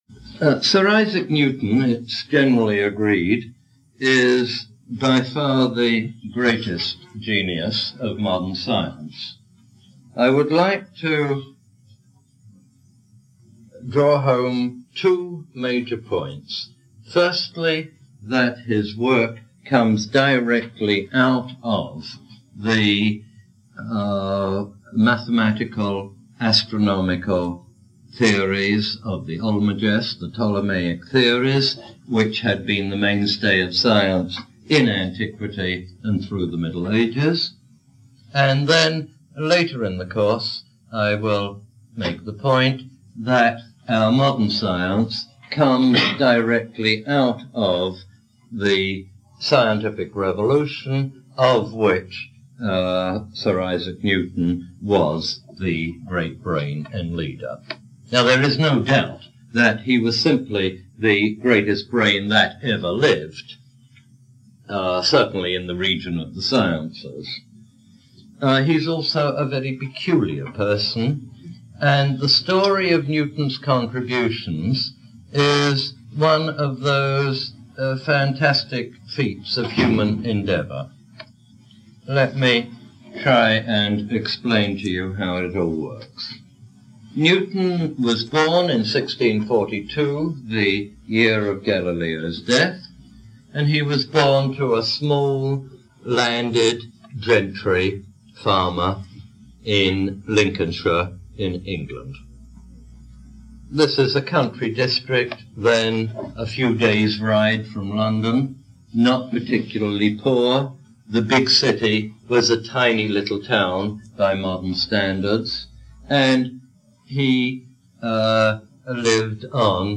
Derek de Solla Price "Neolithic to Now" Lecture #12, Yale 1976.
Derek de Solla Price “Neolithic to Now” Lecture #12